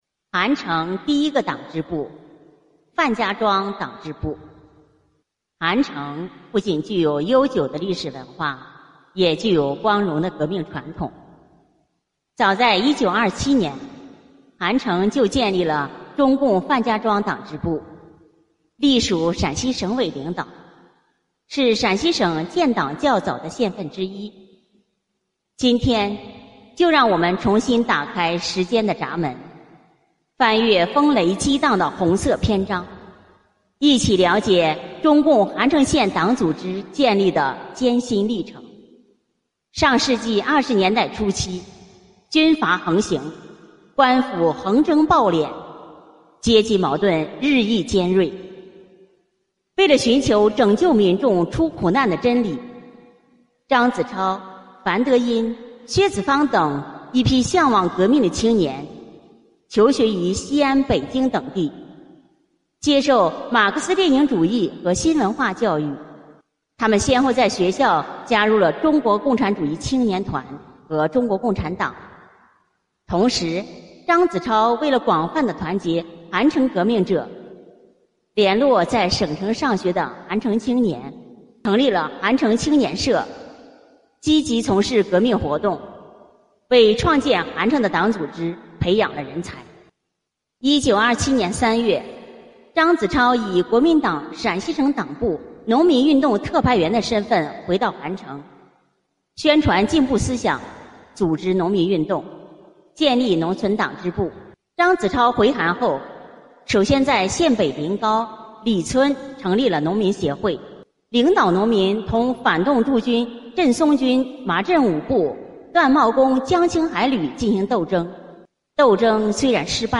【红色档案诵读展播】韩城第一个党支部——范家庄党支部